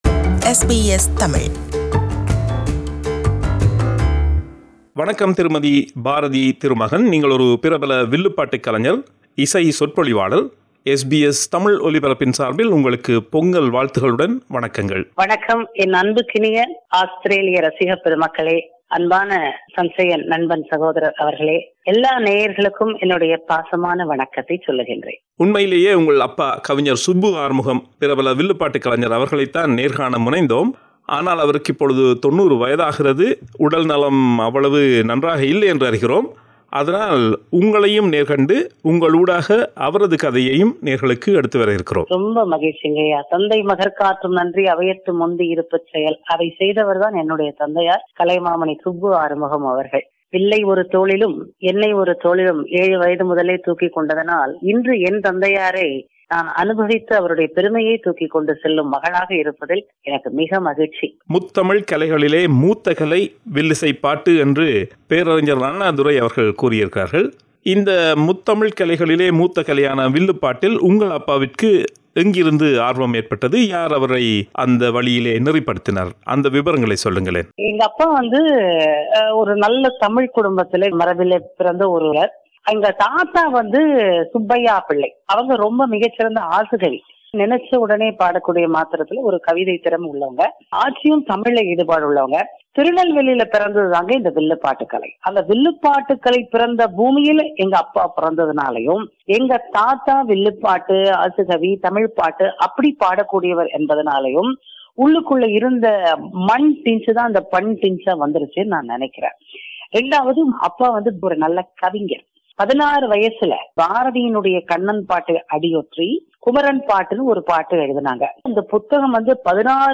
In the first part of this interview